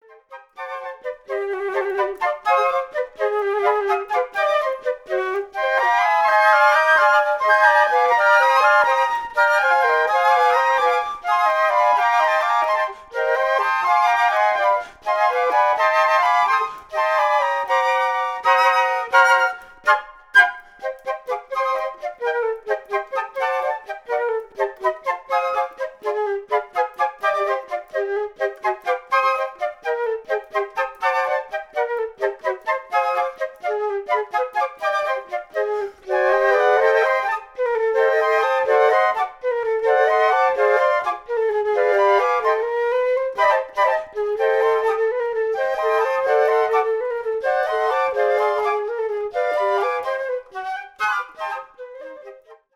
Pour quatuor de flûtes